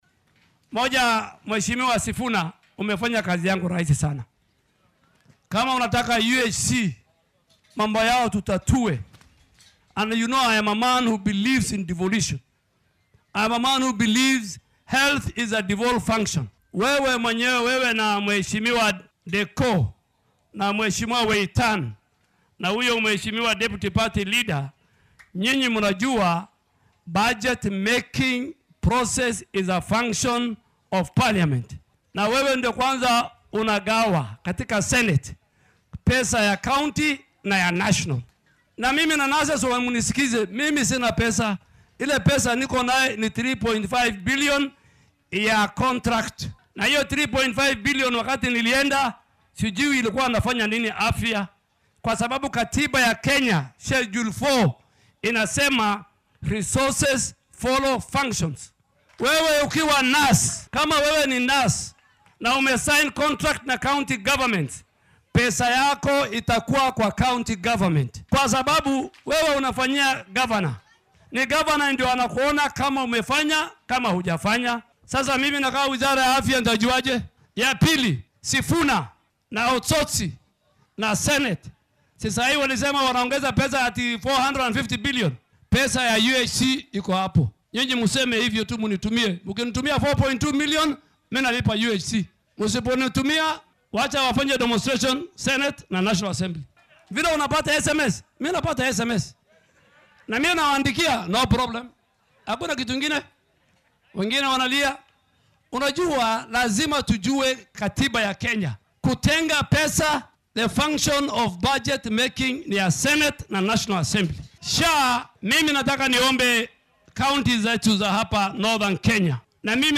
Isagoo ka jawaabaya walaaca ku saabsan xaaladda shaqaalaha UHC oo ay soo jeediyeen Senatorka ismaamulka Nairobi Edwin Sifuna iyo dhiggiisa Vihiga Godfrey Osotsi oo ka qeyb galay xaflad dhaqameed oo ka dhacday Gaarisa, Ducaale ayaa ku boorriyay mudanayaasha inay lacag u qoondeeyaan shaqaalaha si uu awood ugu yeesho inuu bixiyo.